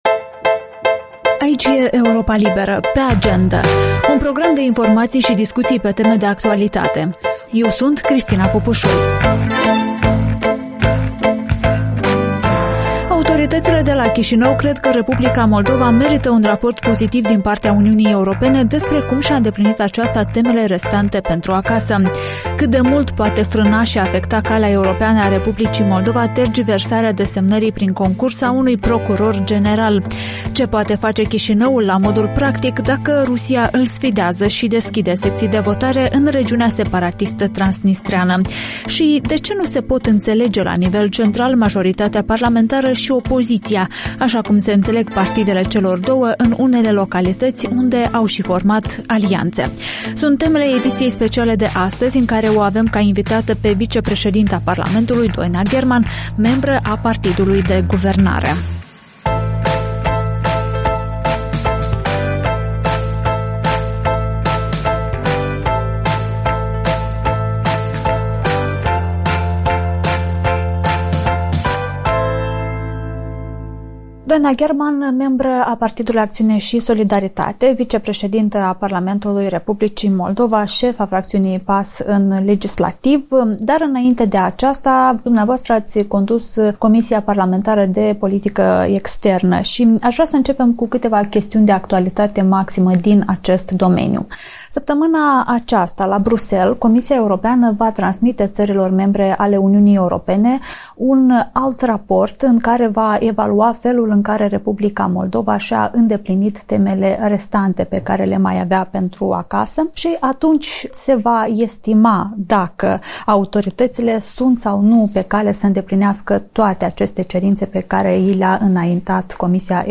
Vicepreședinta Parlamentului, Doina Gherman, vorbește în podcastul video „Pe Agendă” de la Europa Liberă despre felul în care concursul eșuat pentru funcția de procuror general al R. Moldova ar aduce atingere procesului de integrare europeană.